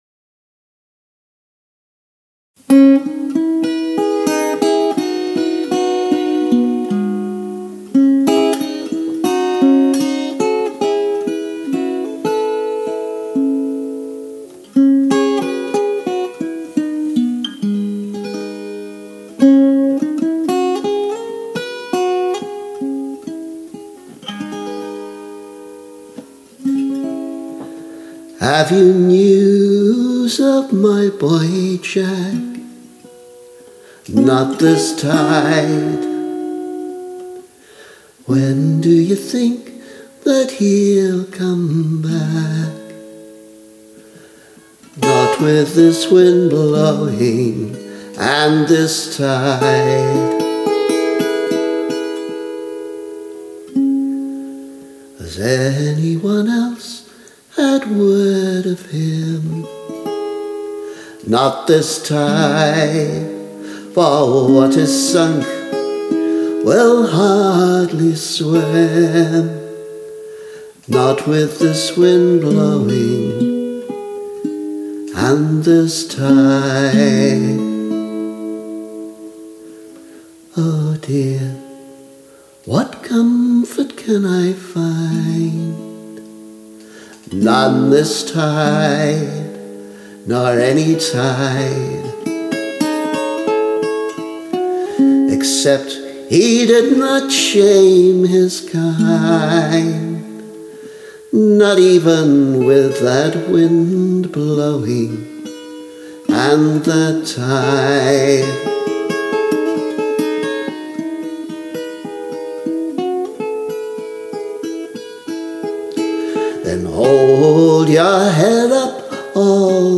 The guitar is a Nashville-strung Baby Taylor. I think the final version of this might have include some double- or triple-tracked vocals. Even if it doesn’t, the vocal needs work.